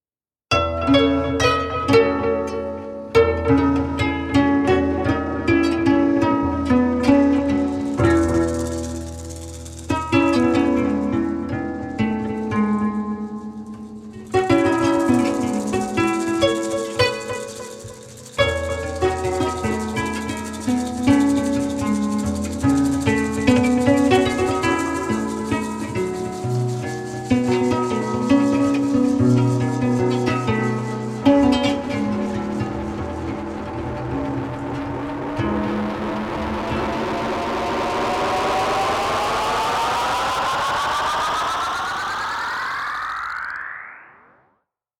全編通して温かく豊かな味わいに満ちた仕上がりとなっています。